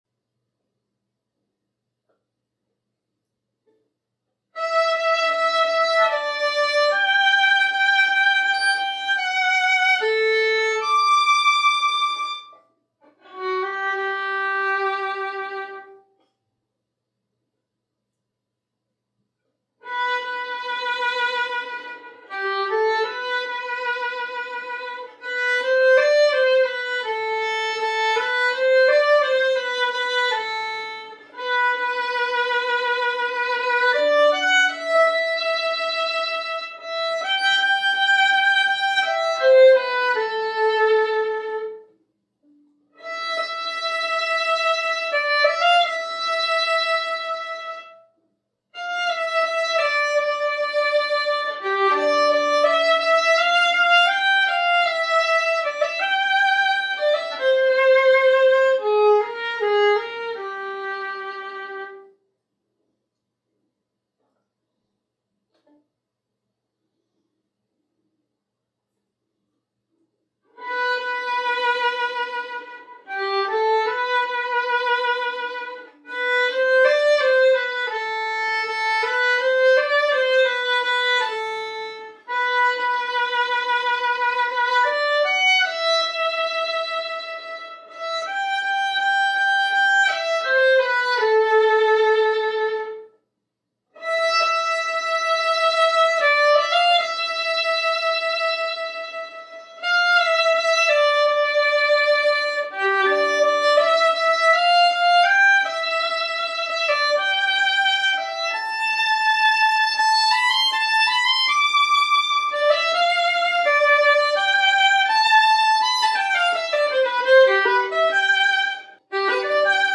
Intermediate Sheet Music
• violin solo